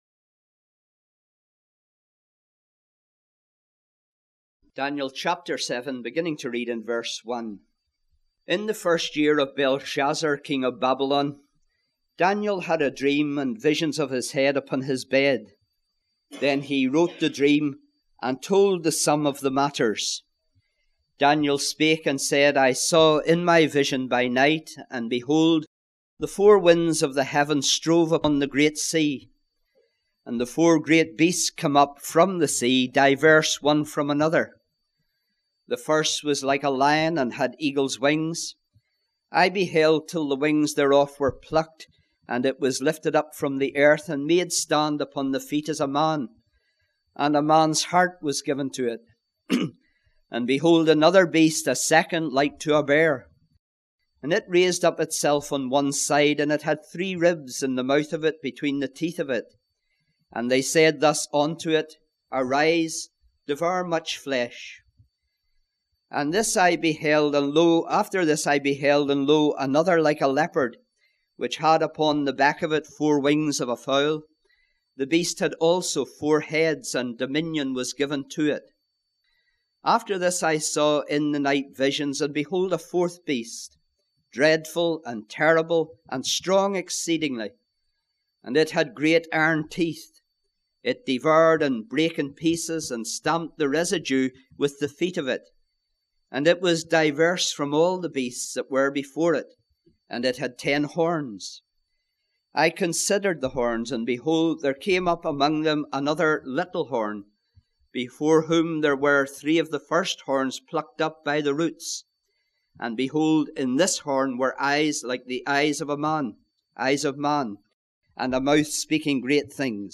The Gospel is expounded and applied in types and shadows. A set of good practical expository study sermons from the Book of Daniel.